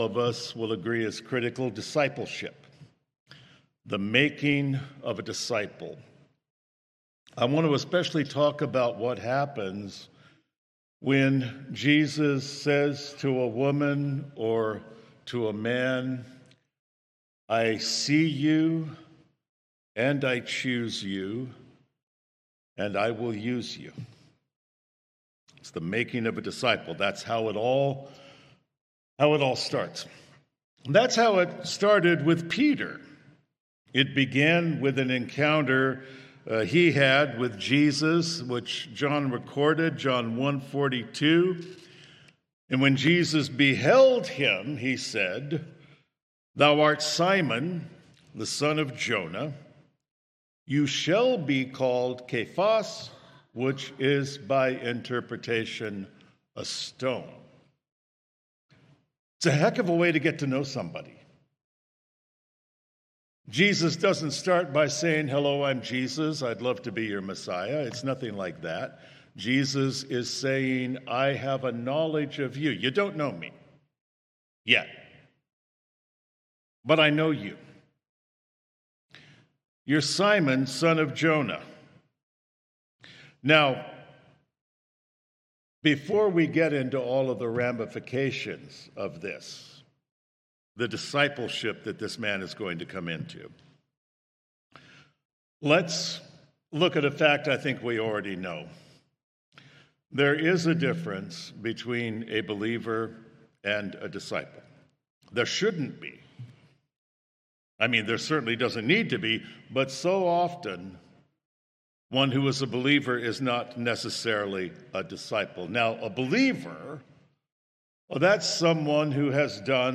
Message: